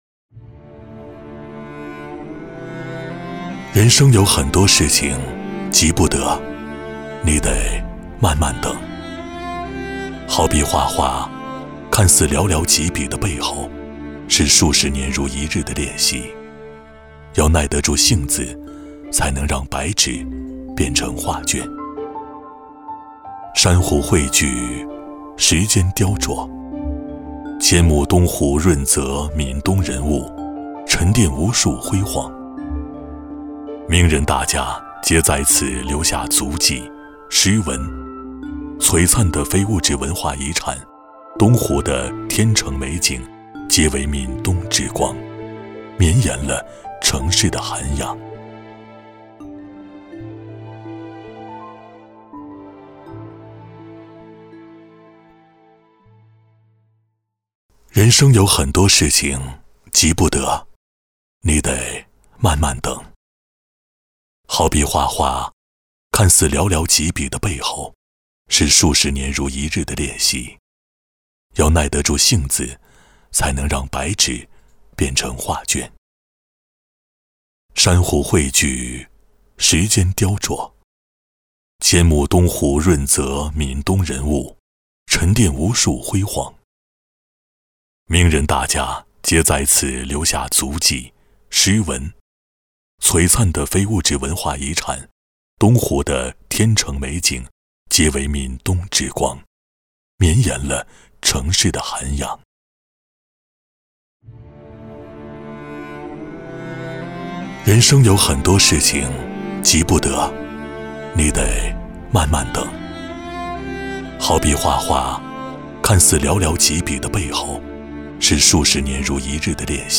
• 男S310 国语 男声 宣传片【大师级】福晟国宾美墅宣传片 大气浑厚磁性|沉稳